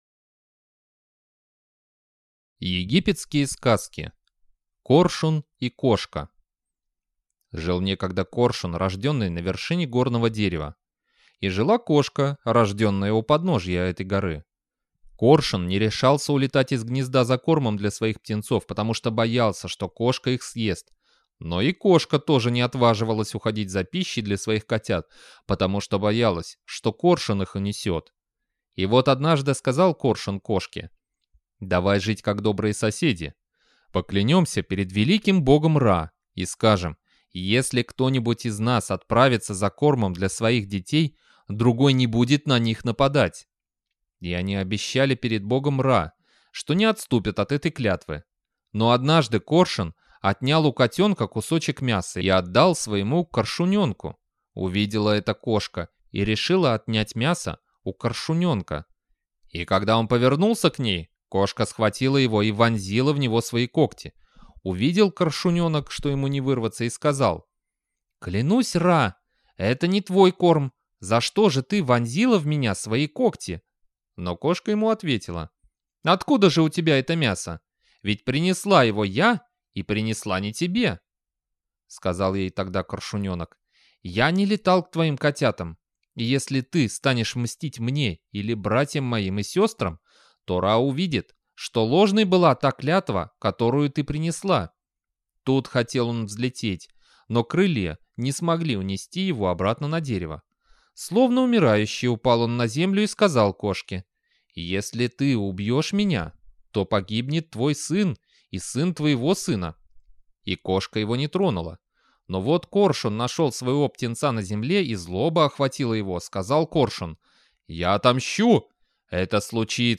Коршун и кошка – арабская аудиосказка
Аудиокнига в разделах